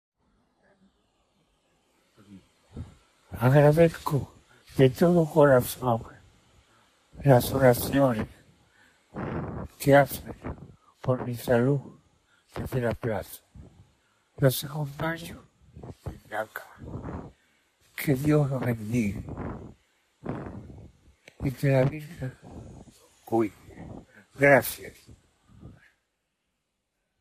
Que Dios los bendiga y que la Virgen los cuide”. refiere el papa con voz convaleciente.
06.03.2025. El Papa Francisco agradece por las oraciones a través de un audio difundido en la Plaza de San Pedro al inicio del rezo del Santo Rosario por su salud esta tarde, 6 de marzo, donde acompañan fieles y colaboradores de la Curia Romana.
Mientras se escuchaba la voz del Papa Francisco en los megáfonos de la Plaza de San Pedro, los presentes manifestaron su emociónate acción de gracias a Dios, al escuchar la voz del Pontífice.